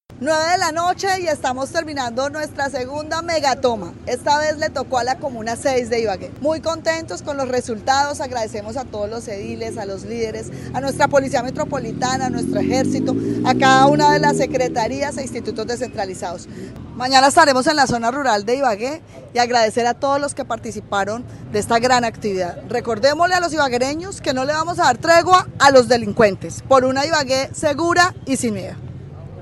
Escuche las declaraciones de la alcadesa de Ibagué, Johana Aranda sobre la ‘Megatoma’ que tuvo lugar en la Comuna 6 de Ibagué: